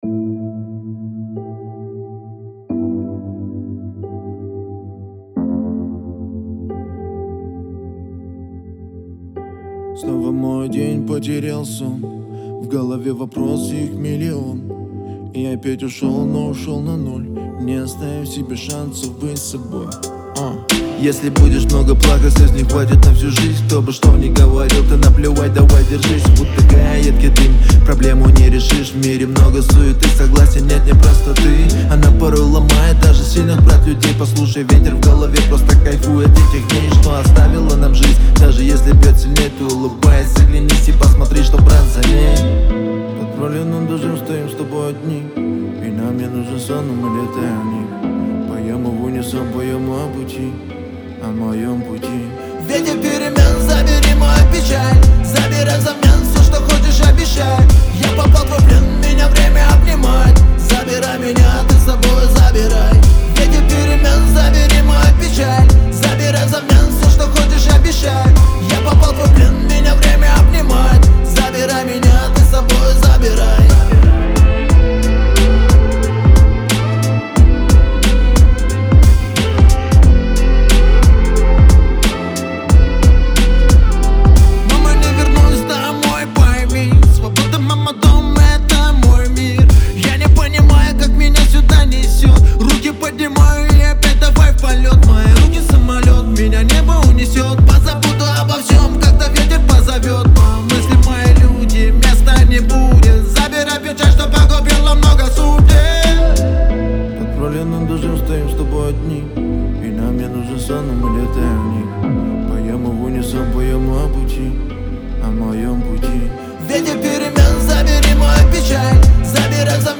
это мощная и вдохновляющая композиция в жанре поп-рок.